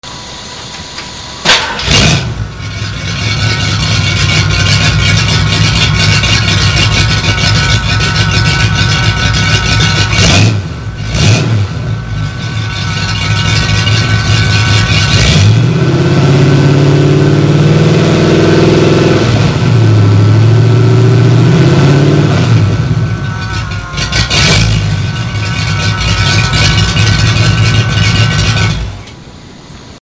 Sound clips of Super 44s mounted temporarily just after the header collector, no other pipe.
outside the car.
44outsidemp3.mp3